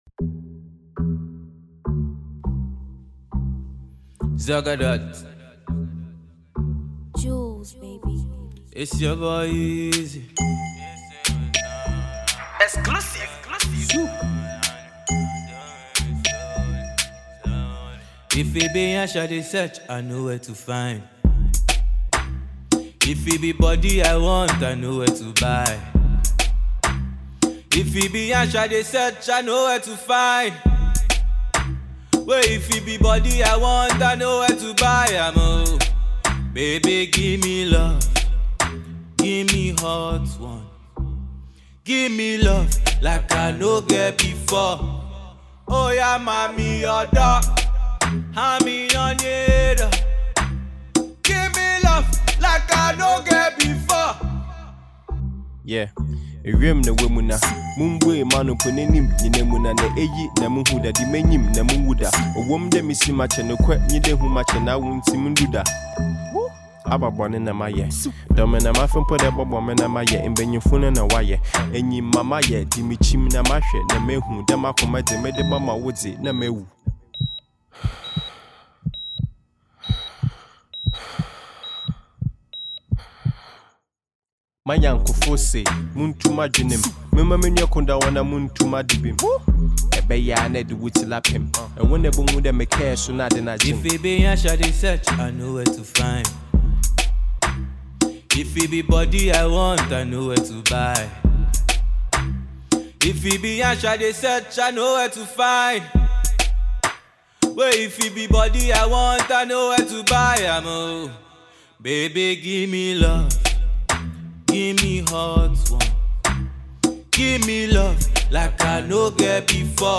Fante rapper
Nigerian singer